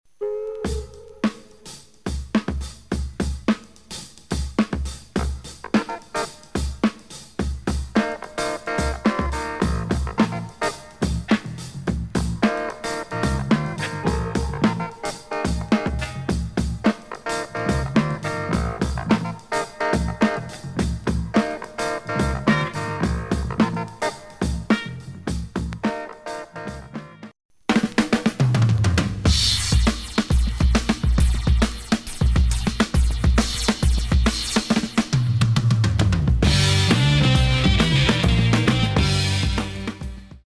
Heavy German funk & fusion
Really fat beats.